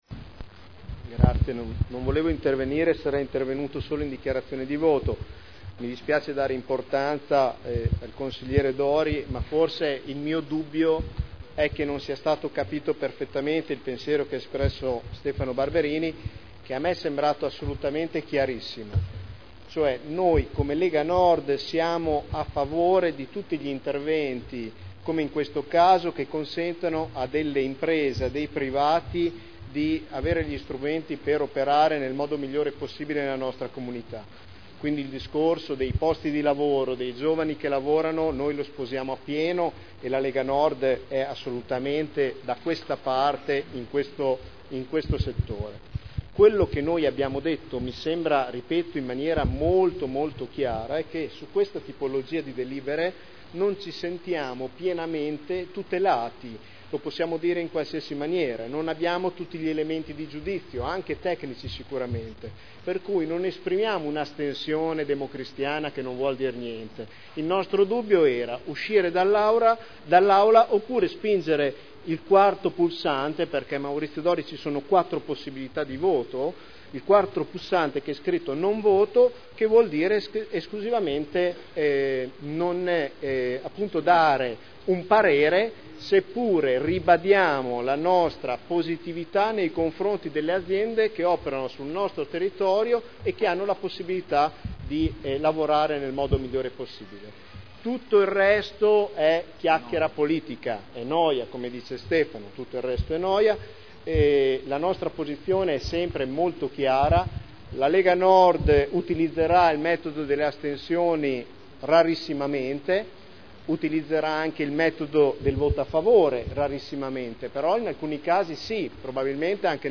Nicola Rossi — Sito Audio Consiglio Comunale
Seduta del 13/12/2010 Deliberazione: Variante al P.O.C.-RUE – AREA in via Emilia Ovest Z.E. 1481-1502 – Adozione Dibattito